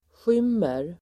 Uttal: [sj'ym:er]